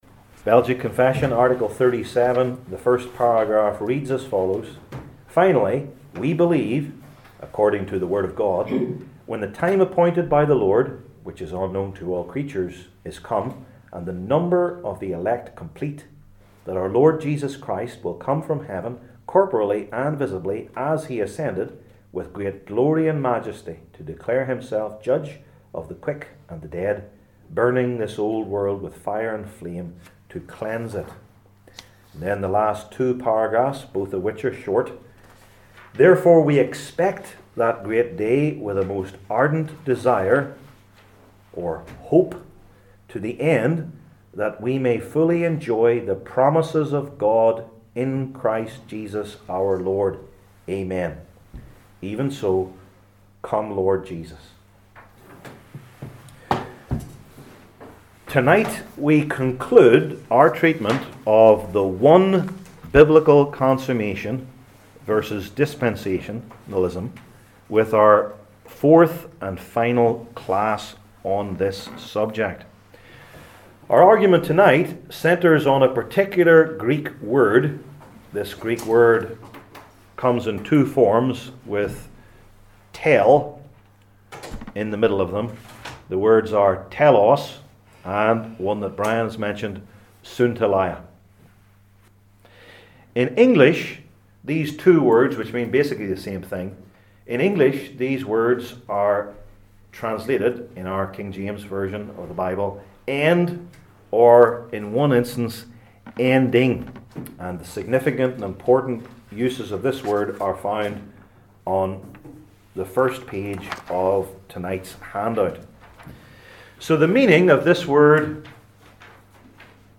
Passage: Matthew 13:36-52 Service Type: Belgic Confession Classes